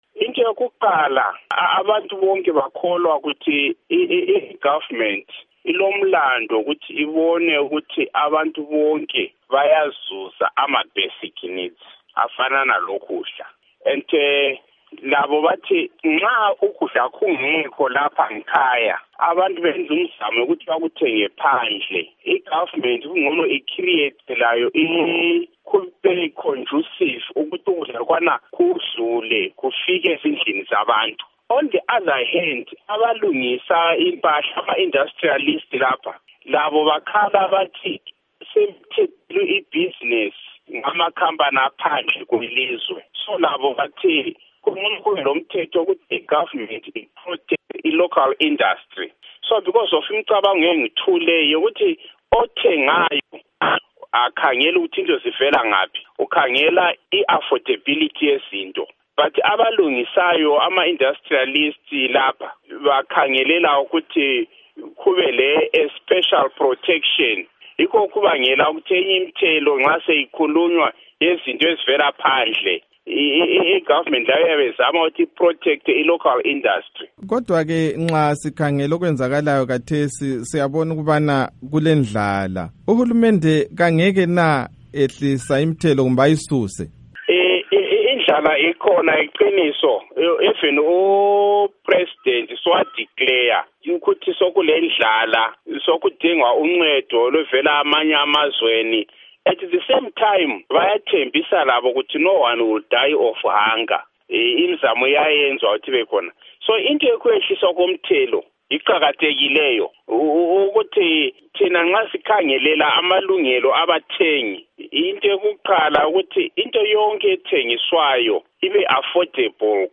Ingxoxo